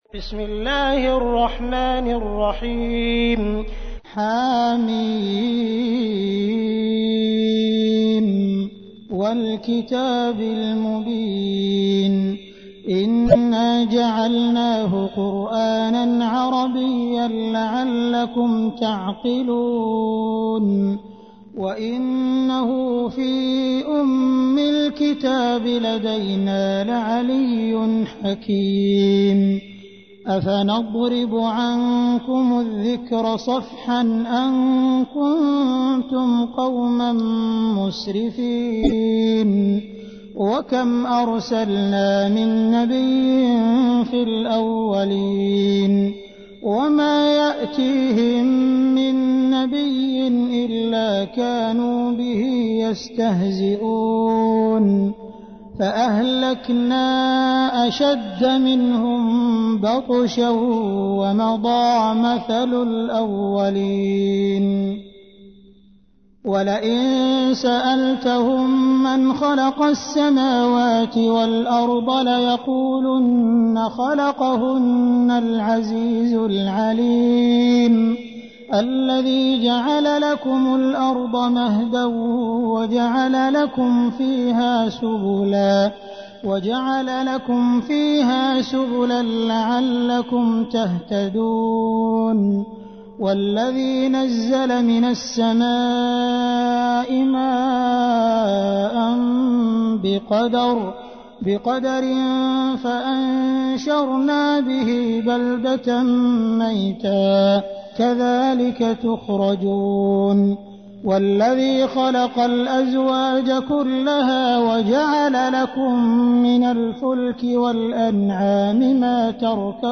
تحميل : 43. سورة الزخرف / القارئ عبد الرحمن السديس / القرآن الكريم / موقع يا حسين